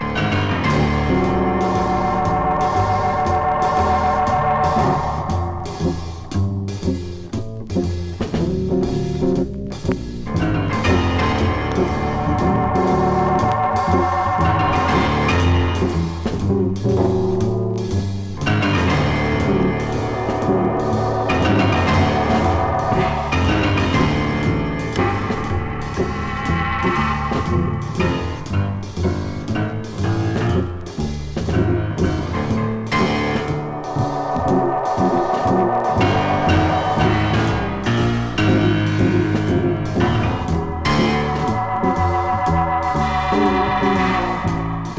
Stage & Screen, Soundtrack　USA　12inchレコード　33rpm　Stereo